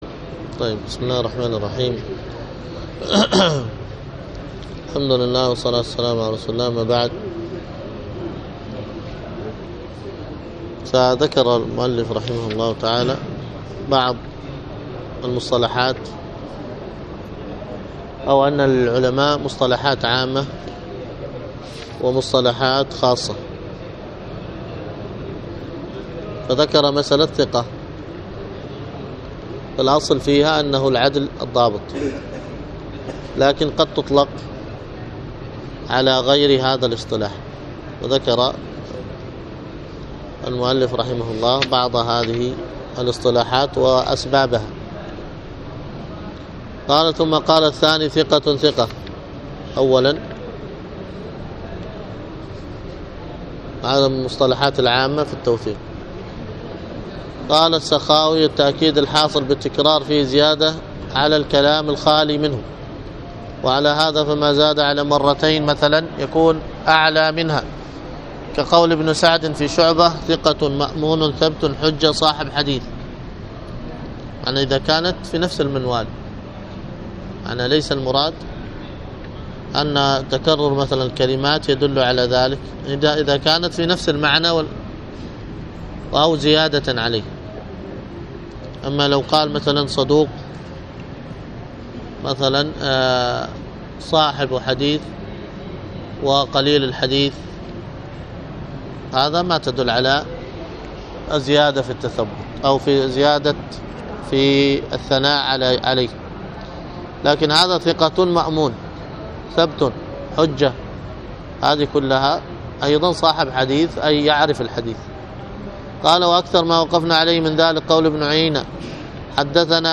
الدرس في شرح كتاب ضوابط الجرح والتعديل 75